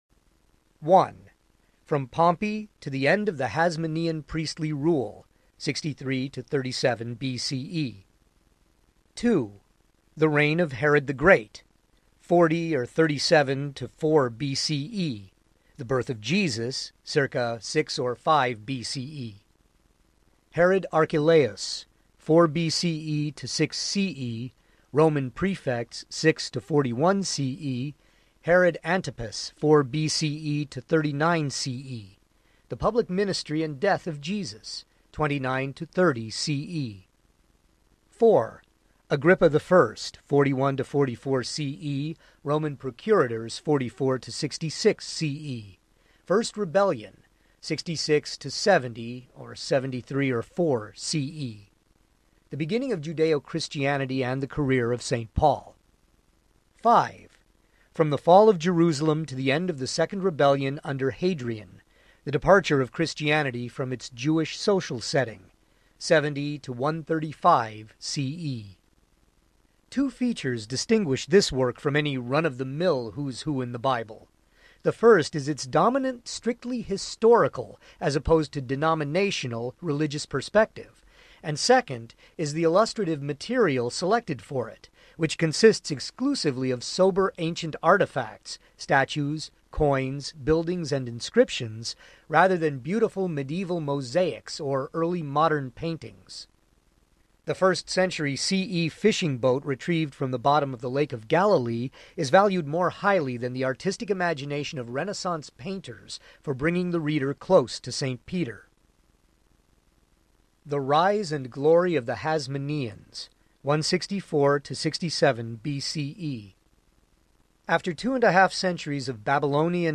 Who’s Who in the Age of Jesus Audiobook
Narrator
8.5 Hrs. – Unabridged